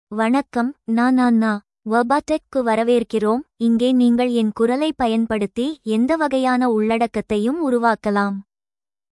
Anna — Female Tamil AI voice
Anna is a female AI voice for Tamil (India).
Voice sample
Listen to Anna's female Tamil voice.
Anna delivers clear pronunciation with authentic India Tamil intonation, making your content sound professionally produced.